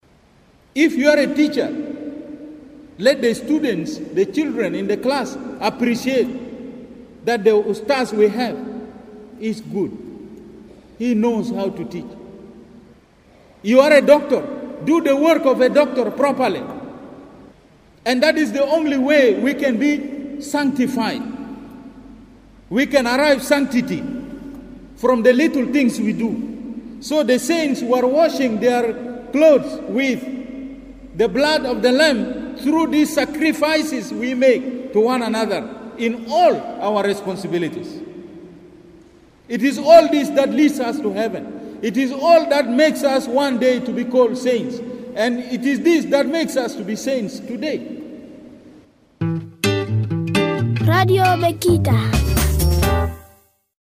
Marking Solemnity of All Saints on Tuesday, His Lordship Santo Loku Pio urges faithful at St Theresa Cathedral to be professional in their jobs so that they may reach Sanctity stage like All Saints.